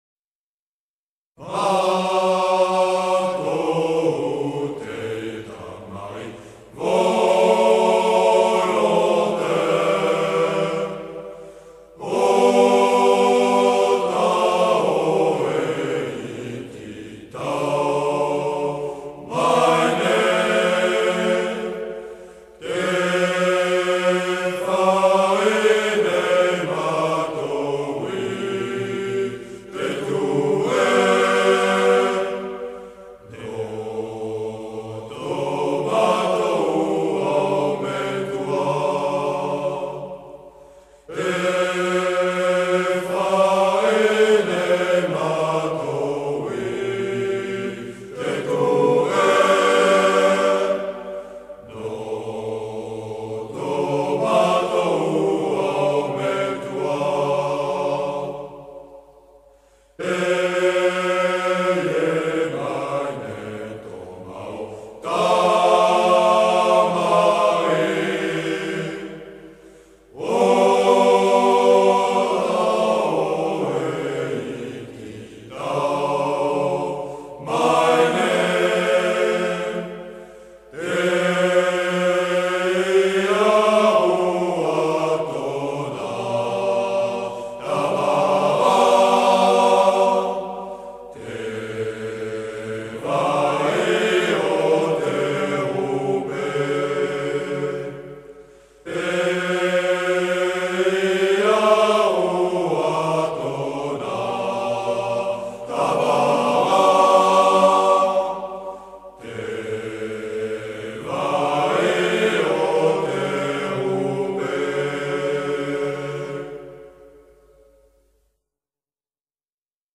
Le chant régimentaire du régiment d'infanterie de marine du Pacifique - Polynésie
Le chant régimentaire du régiment d'infanterie de marine du Pacifique - Polynésie (le chant des Tamari’i Volontaires) est entonné à l’occasion des couleurs et rassemblements régimentaires et l’Hymne des Troupes de marine durant les visites d’autorités, les repas de corps, les grandes réunions ainsi que la cérémonie de commémoration des combats de Bazeilles, fête des troupes de marine.